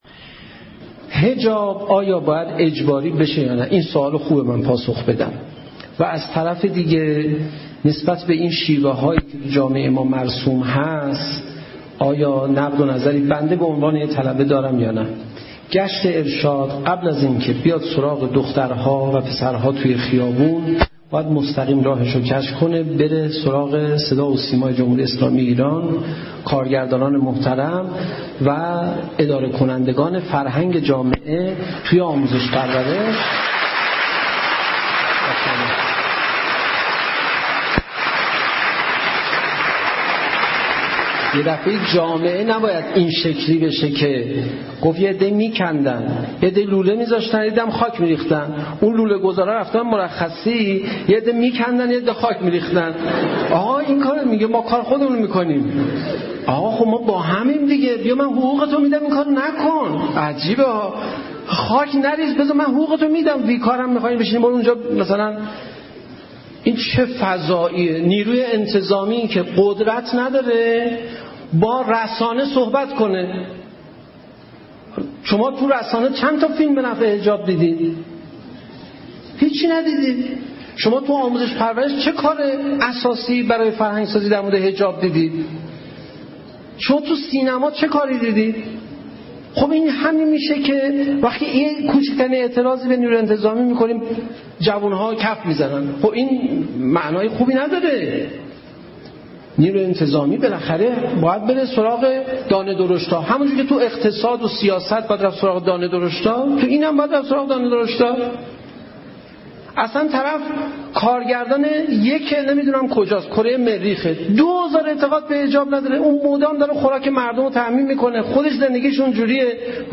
در بخشی از سخنان خود در جمع دانشجویان، ضمن پاسخ گویی به این سوال ها، نظر خود را نسبت به شیوه های مرسوم در جامعه بیان می دارد.